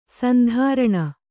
♪ sandhāraṇa